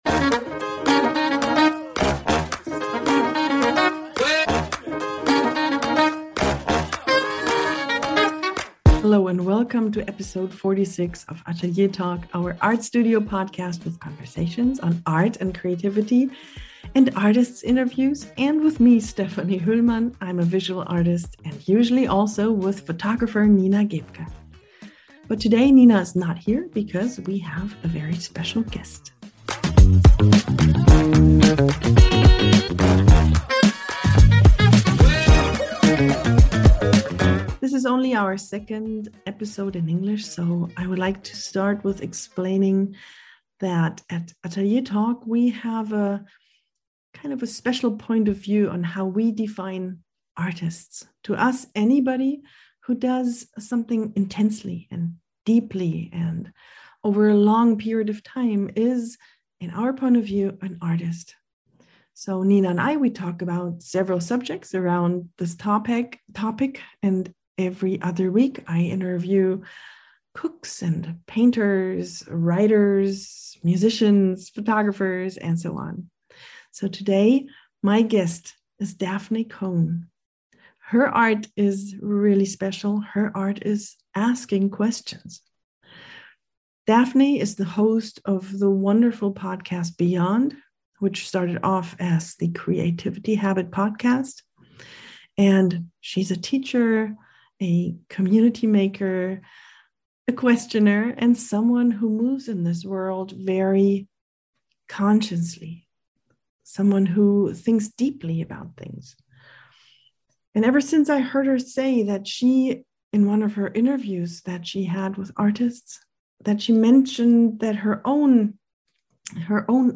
A Conversation on the Power, Freedom, and Beauty of Questions